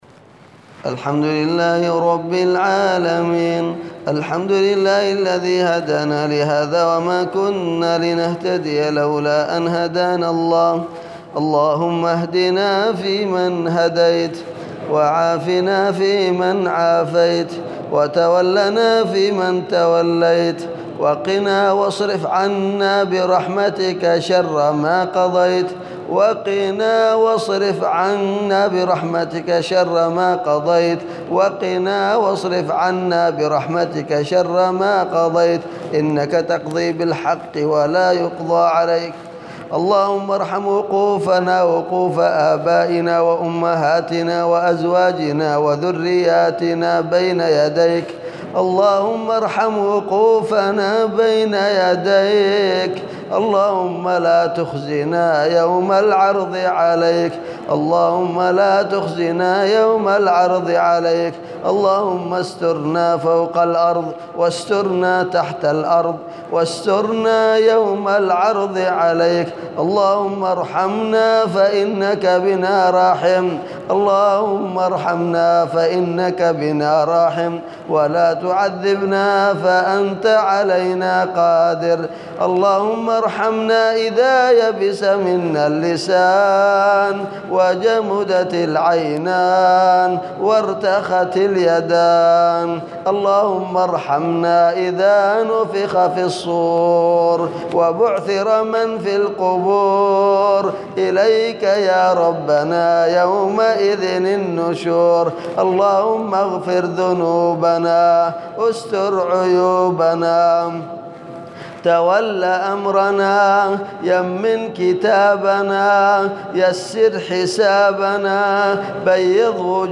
أدعية وأذكار
دعاء خاشع ليلة 25 رمضان 1438هـ